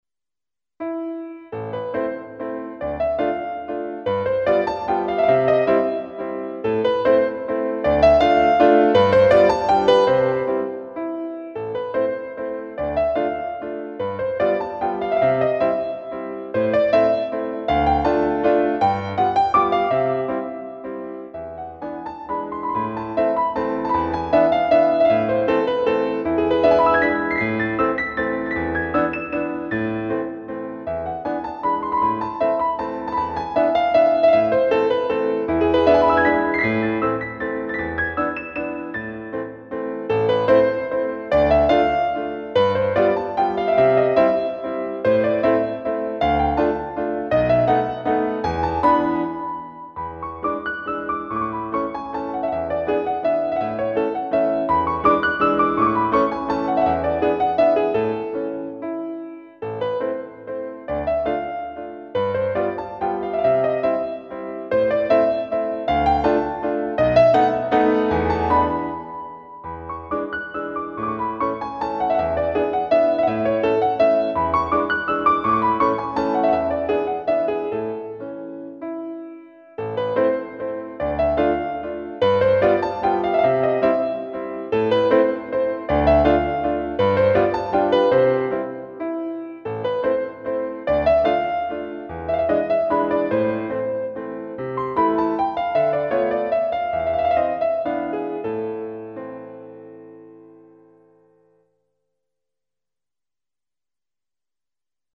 made with "Miroslav Philharmonik"
CLASSICAL MUSIC ; ROMANTIC MUSIC